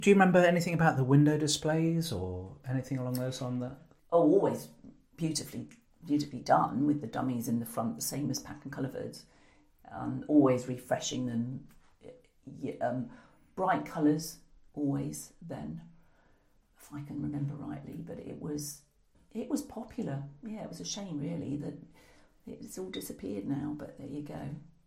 The wonderful window displays - Packs' People Oral History project